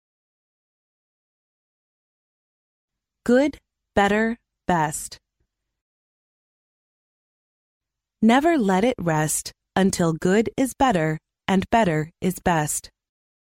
幼儿英语童谣朗读(MP3+中英字幕) 第54期:好较好最好 听力文件下载—在线英语听力室